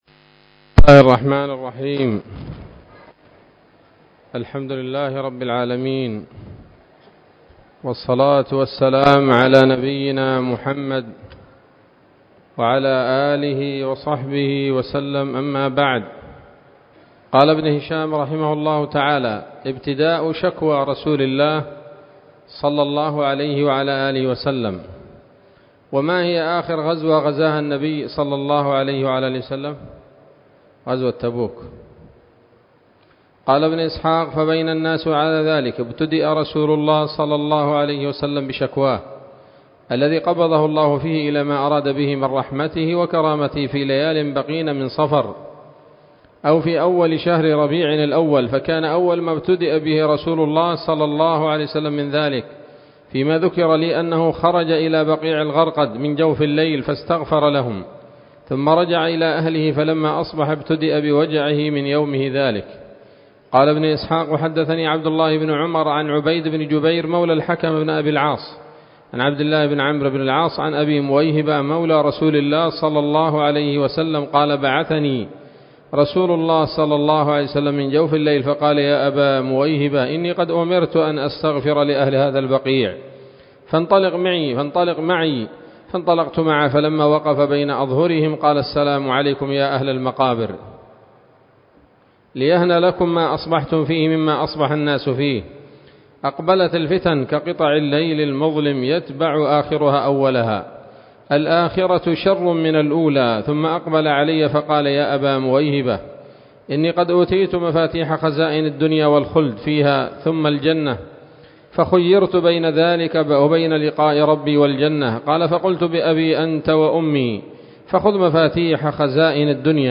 الدرس الرابع والتسعون بعد المائتين من التعليق على كتاب السيرة النبوية لابن هشام